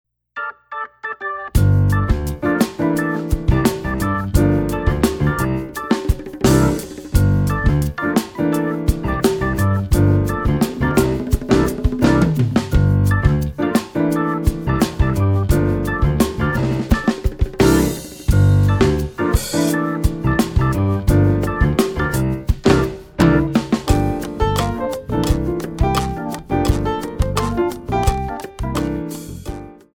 Latin
8 bar intro
Latin Rock